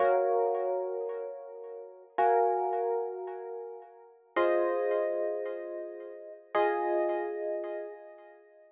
03 ElPiano PT2.wav